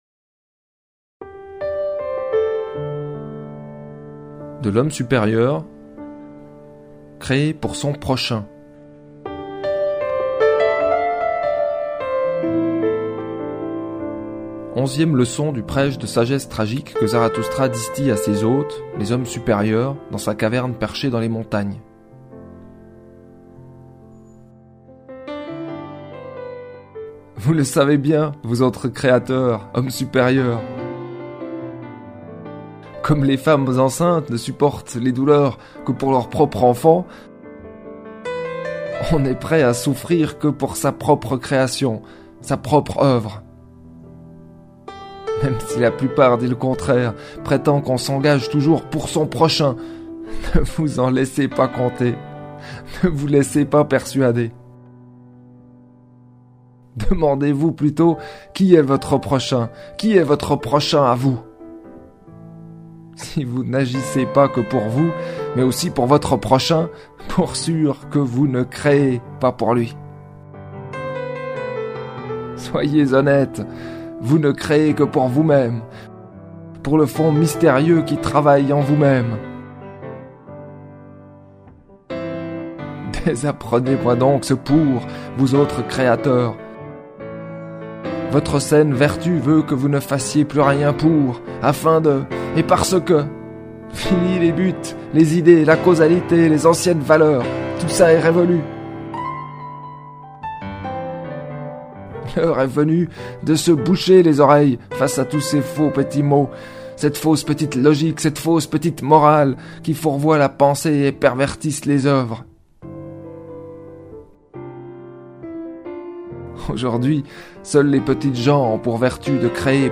Musique : Keith Jarrett, Köln Concert, 1975.